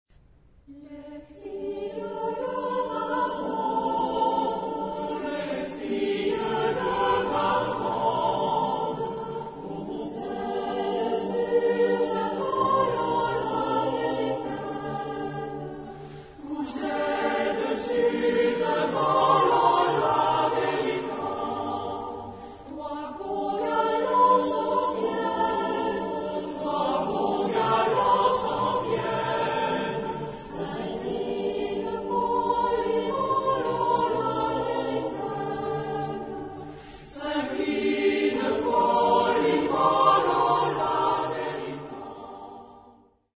Genre-Style-Forme : Chanson ; Folklore ; Profane
Type de choeur : SATB  (4 voix mixtes )
Tonalité : sol mineur